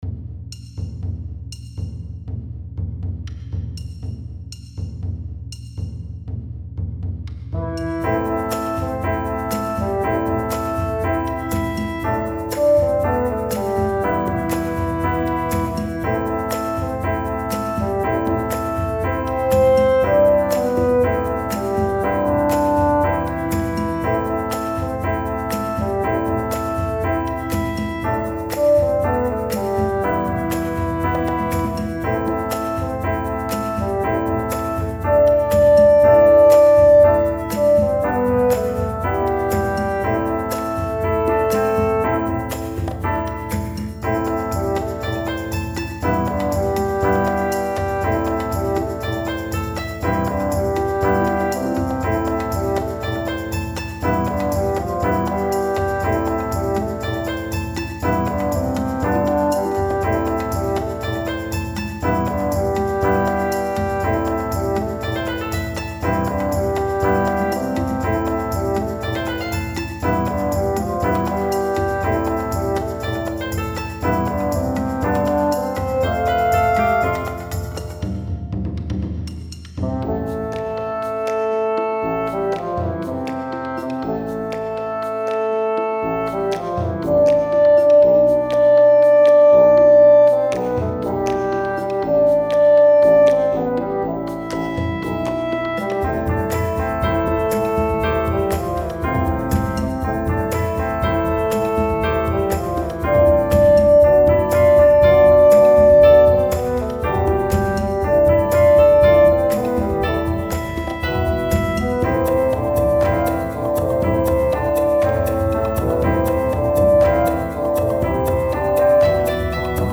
ロング暗い民族